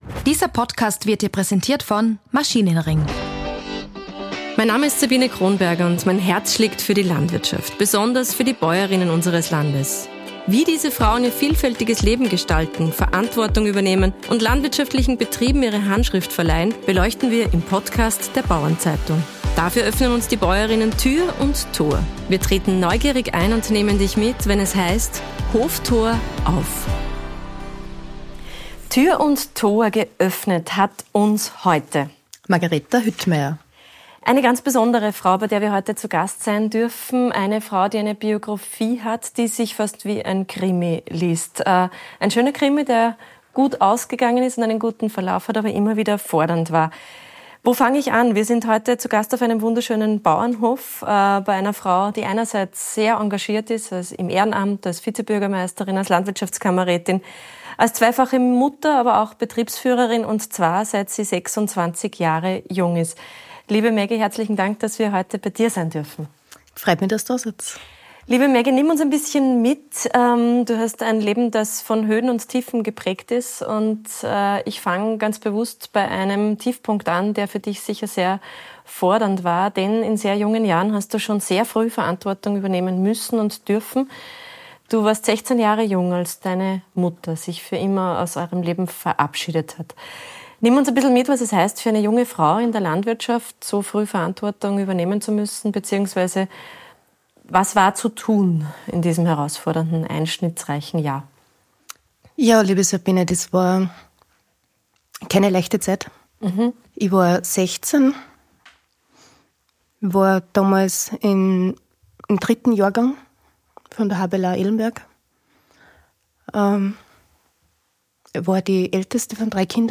Im Gespräch geht es um frühe Verantwortung, weibliche Stärke und die Herausforderung, Familie und Betrieb in Einklang zu bringen. Offen berichtet sie, wie sie Rückschläge meistert, warum ihr Netzwerke so wichtig sind und wie sie andere Frauen ermutigt, ihren eigenen Weg zu gehen.